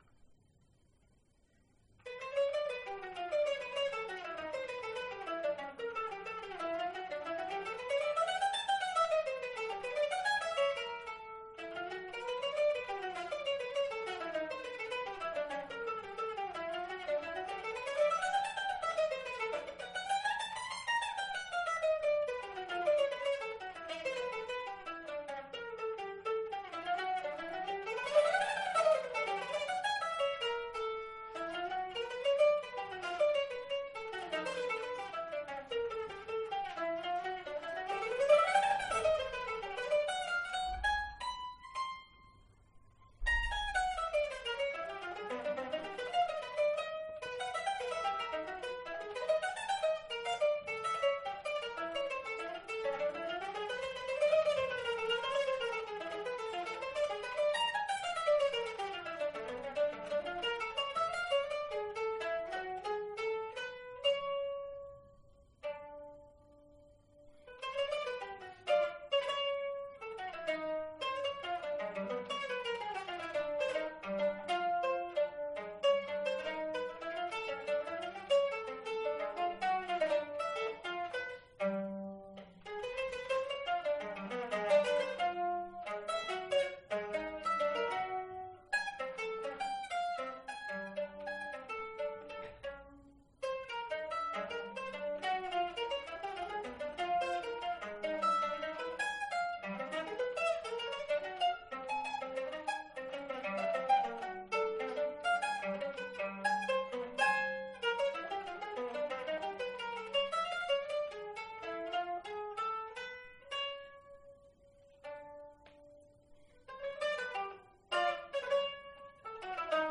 pour mandoline